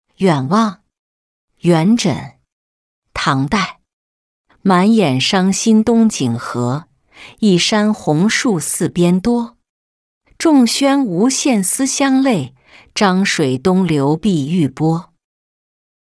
远望-音频朗读